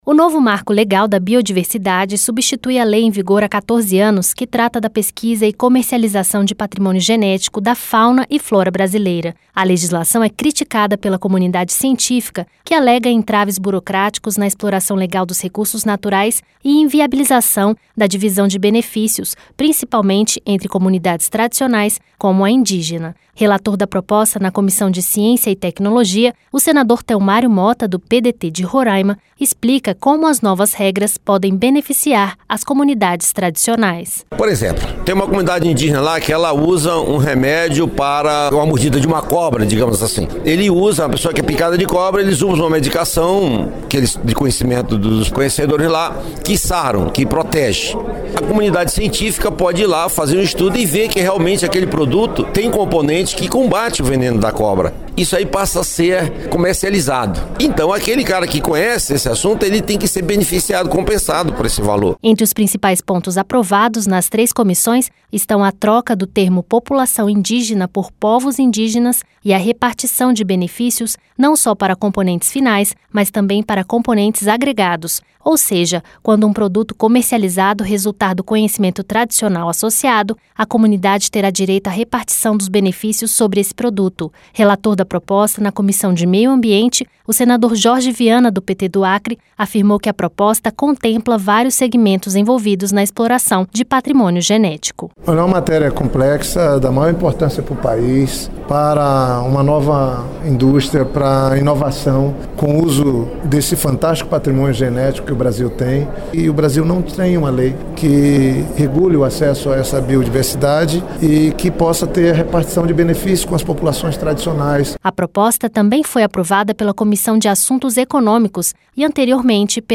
Relator da proposta na Comissão de Ciência e Tecnologia, o senador Telmário Mota, do PDT de Roraima, explica como as novas regras podem beneficiar as comunidades tradicionais.